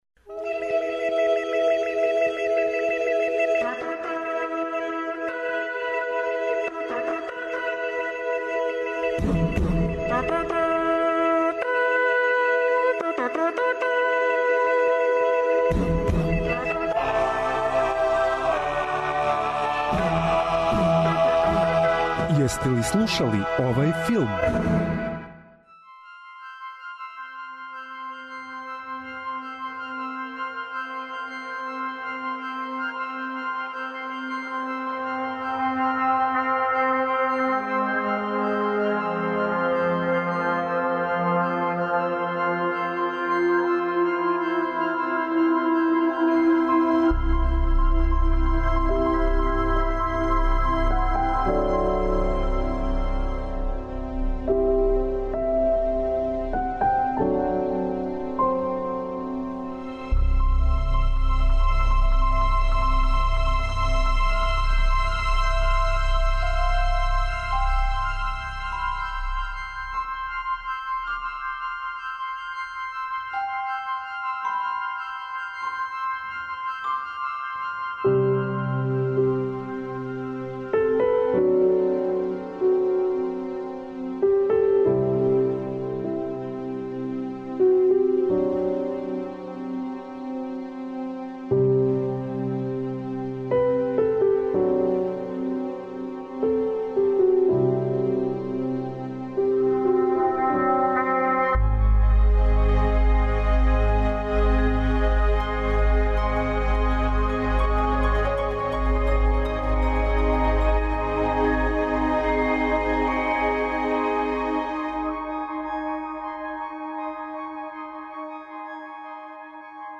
Филмска музика и филмске вести. Заједно ћемо се присетити мелодија које ће нам вратити у сећање сцене из филмова, али и открити шта нам то ново спремају синеасти и композитори.